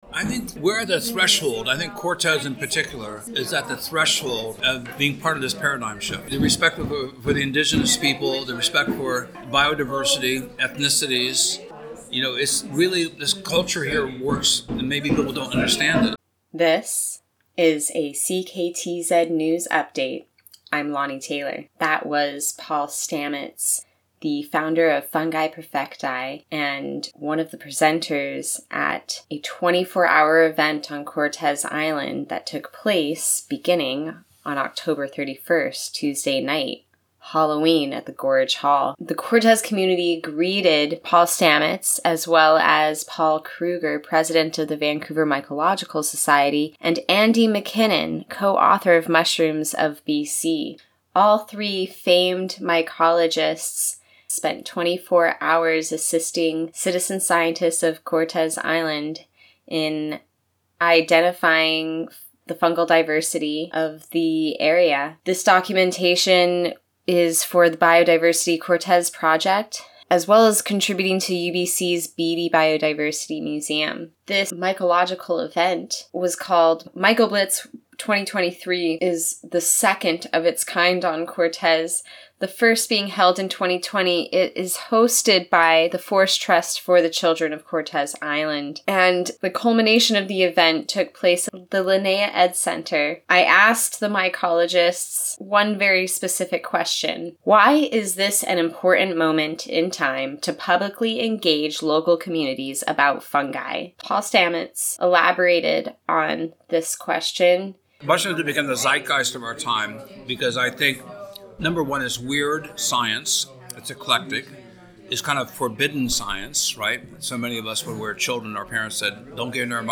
CKTZ attended the event at the Linnaea Education Centre on Nov. 1 which saw about 80 participants.
CKTZ-News-Final-Cut-Mycoblitz-2023.mp3